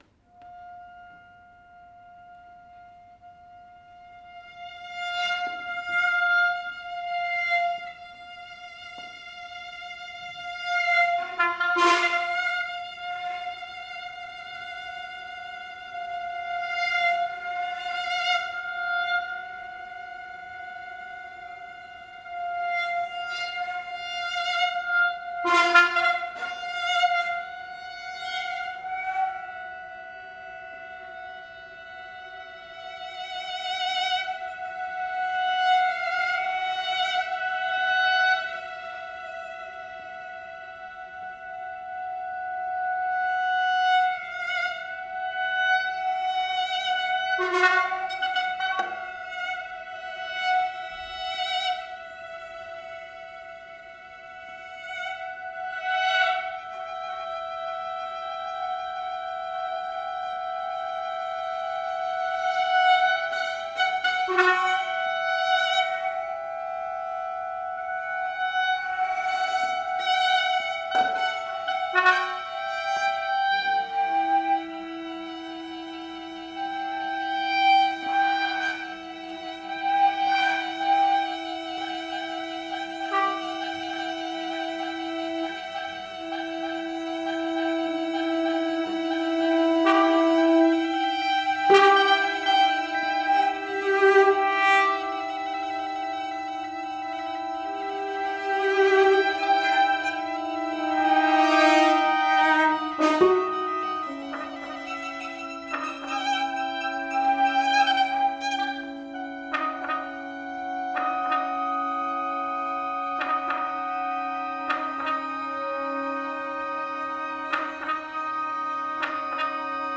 chamber music